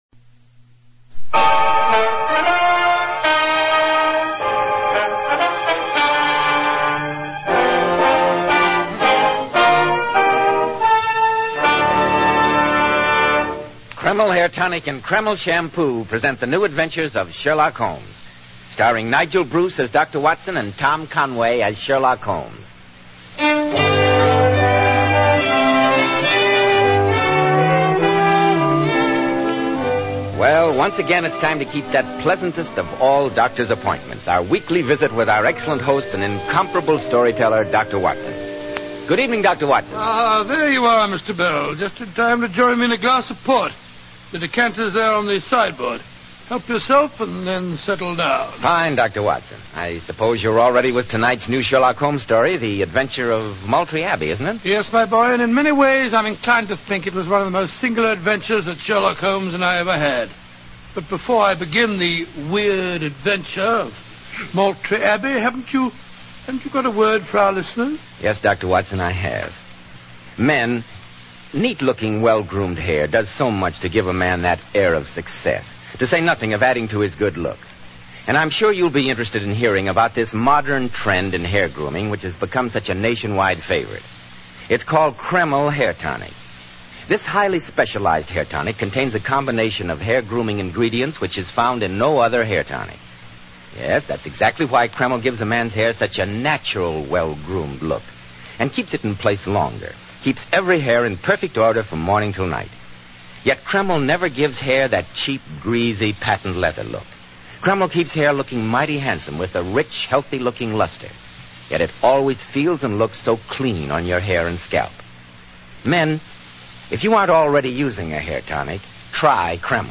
Radio Show Drama with Sherlock Holmes - The Maltree Abbey 1947